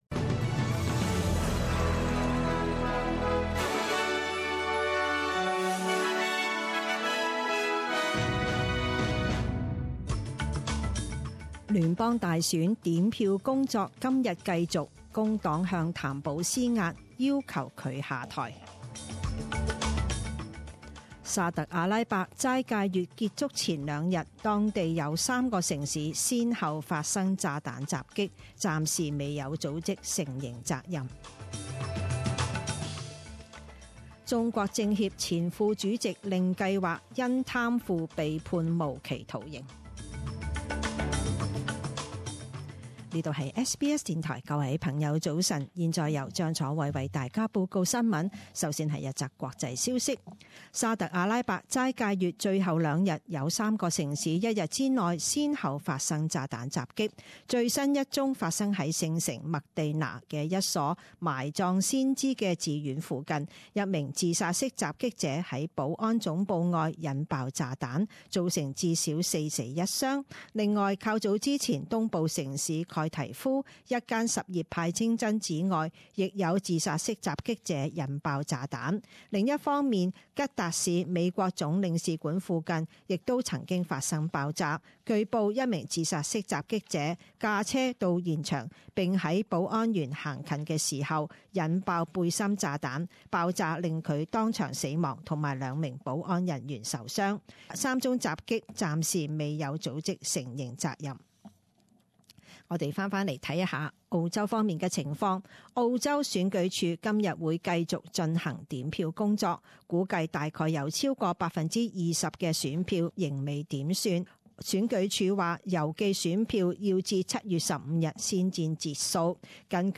七月五日十点钟新闻报导